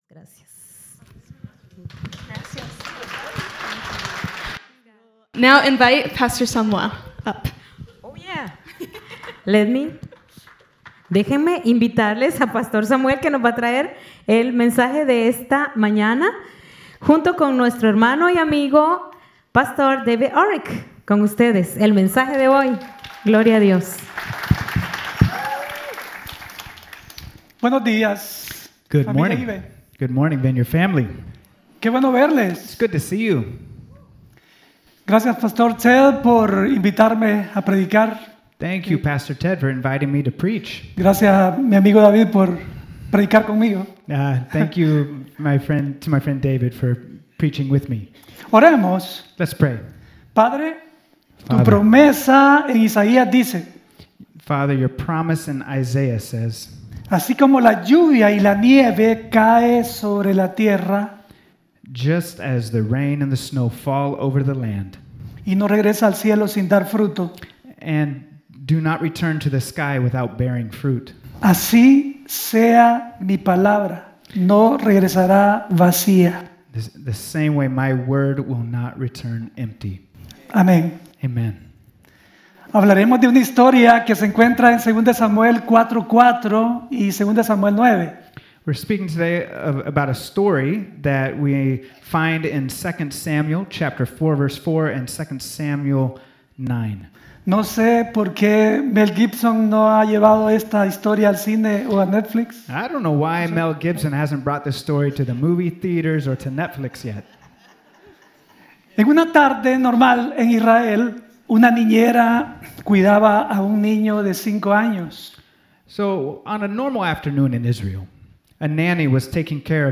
to preach at Evanston Vineyard